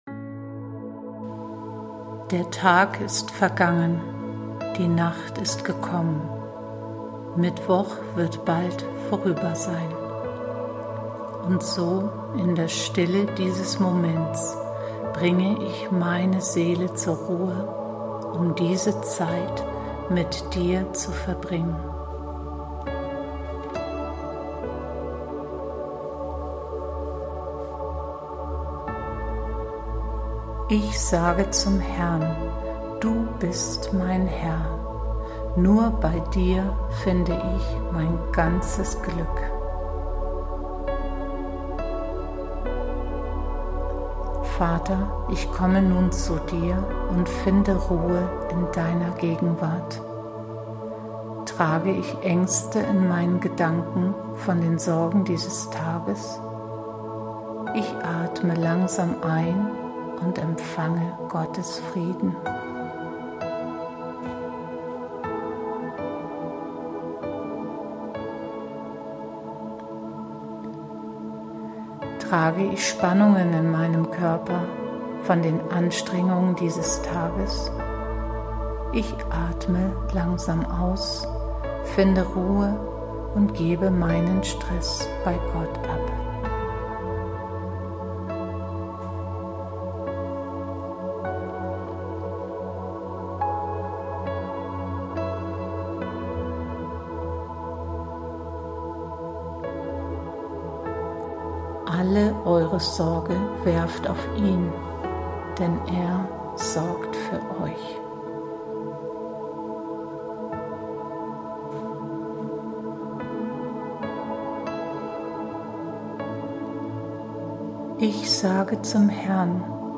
Abendmeditation am 06.09.23